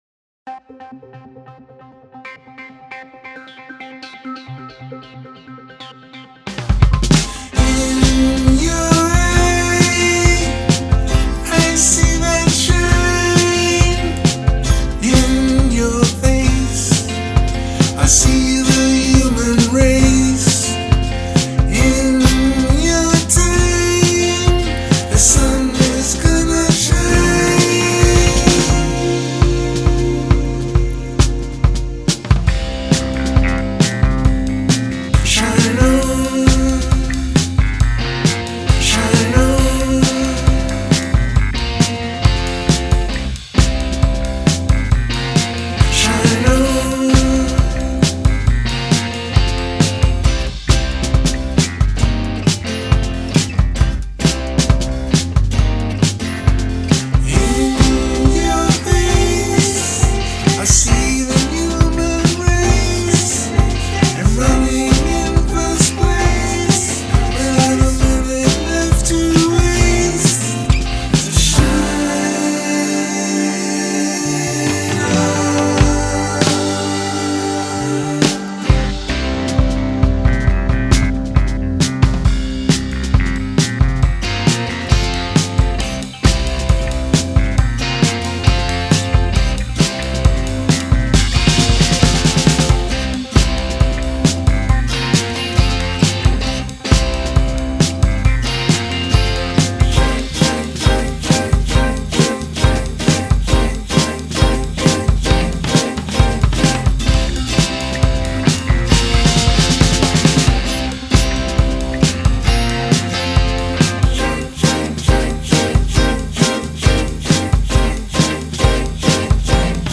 70's Classic Rock tune.
All I have to mix on is Mackie CR4s right now.